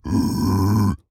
Minecraft Version Minecraft Version 25w18a Latest Release | Latest Snapshot 25w18a / assets / minecraft / sounds / mob / piglin_brute / idle8.ogg Compare With Compare With Latest Release | Latest Snapshot